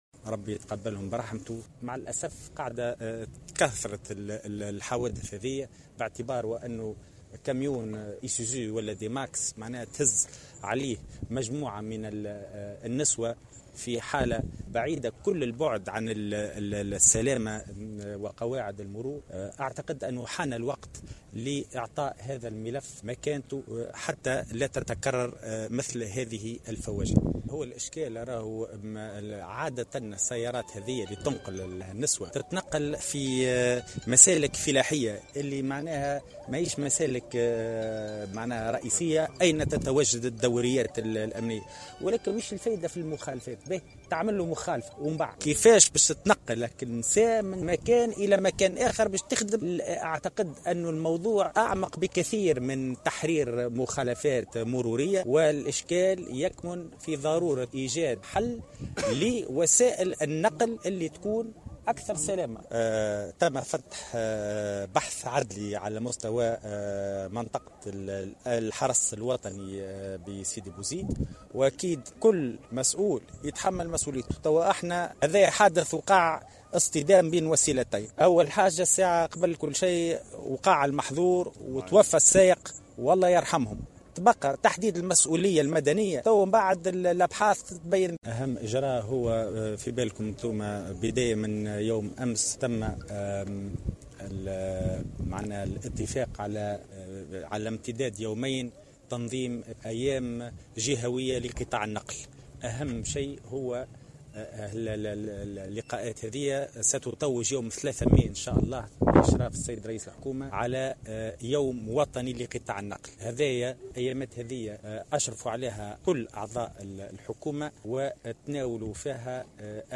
قال وزير الداخلية، هشام الفراتي، لدى حضوره اليوم السبت جنازة ضحايا حادث منطقة "الشارع" من معتمدية السبالة بولاية سيدي بوزيد، إنه تم فتح بحث عدلي على مستوى منطقة الحرس الوطني بسيدي بوزيد وسيتم تحديد المسؤولية لهذا الحادث.